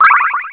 connecttone.wav